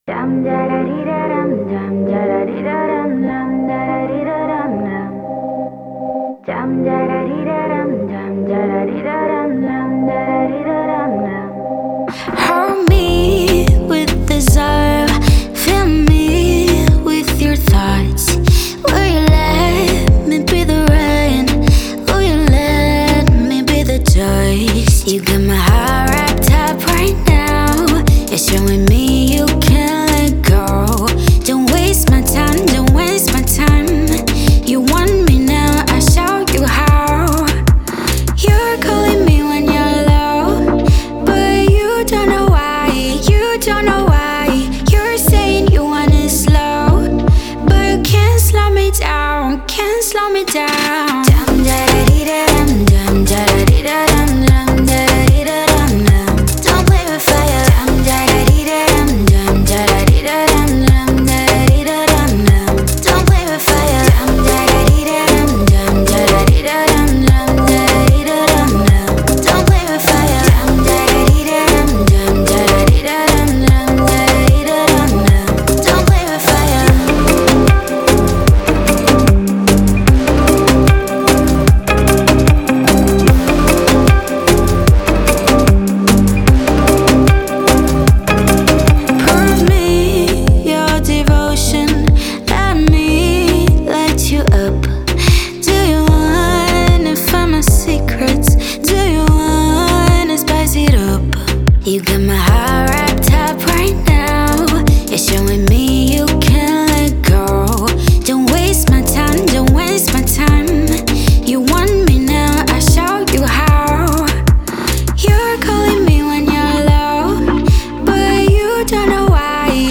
это энергичная песня в жанре поп-рок